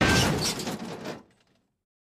Car Crash Cartoon Sfx Sound Effect Download: Instant Soundboard Button